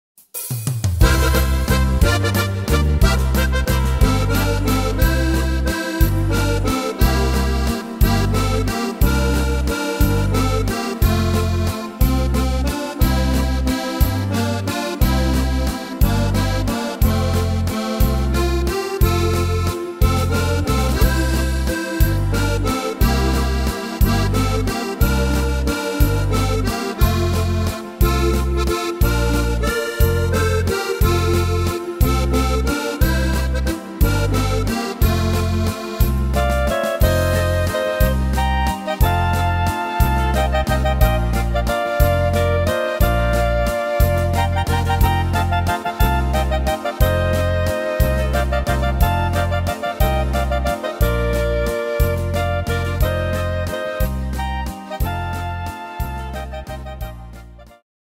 Tempo: 180 / Tonart: F-Dur